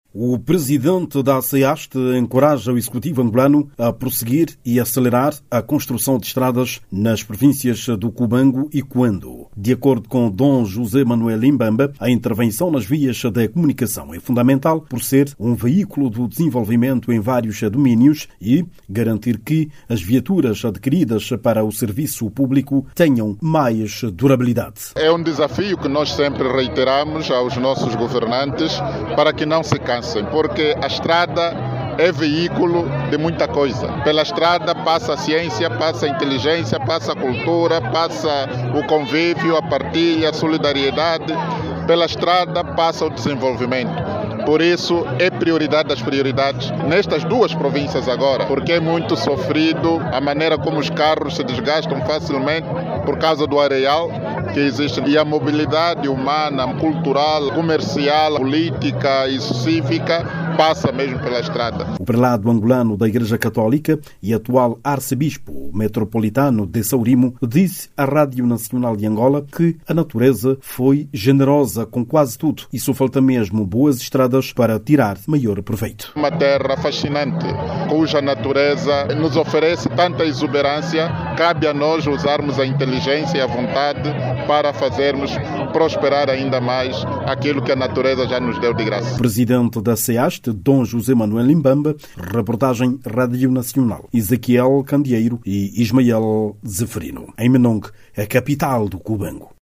Dom Manuel Imbamba fez estas considerações durante a sua visita à província do Cuando Cubango.